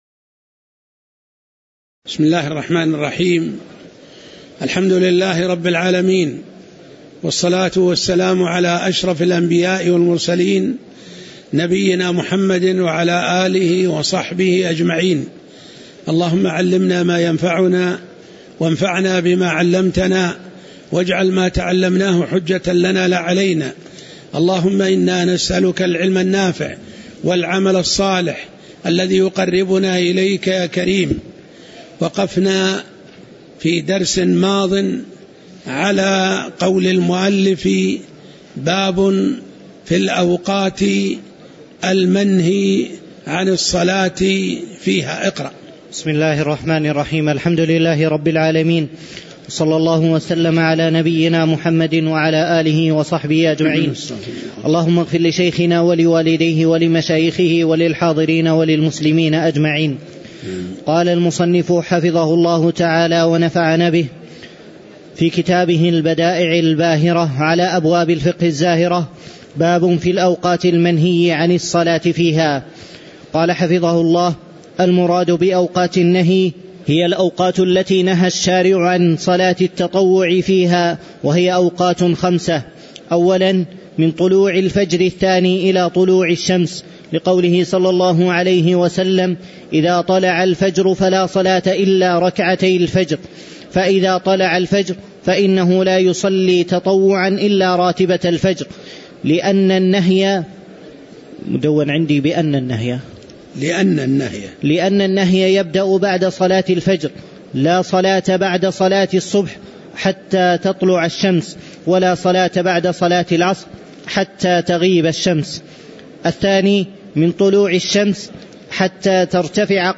تاريخ النشر ٢٩ ربيع الثاني ١٤٣٩ هـ المكان: المسجد النبوي الشيخ